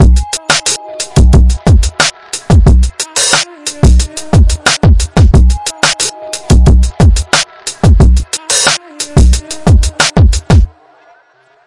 迷你嘻哈节拍 " 嘻哈配音
描述：这个鼓环有一个圆润的氛围
Tag: 循环 的PERC 舞蹈 鼓循环 节拍 敲击环 时髦 击败 啤酒花 常规